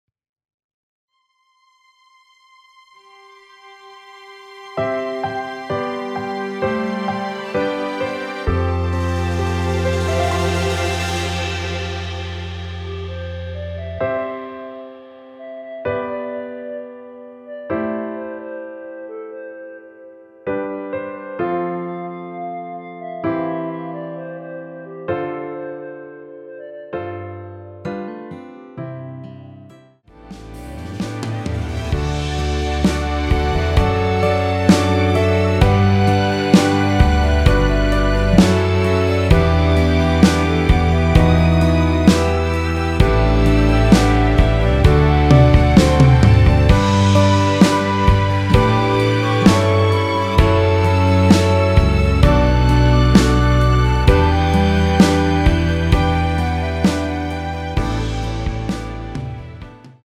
원키에서(+3)올린 멜로디 포함된 MR입니다.(미리듣기 확인)
앞부분30초, 뒷부분30초씩 편집해서 올려 드리고 있습니다.
(멜로디 MR)은 가이드 멜로디가 포함된 MR 입니다.